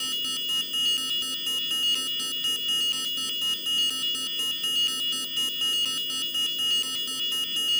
Alarm.wav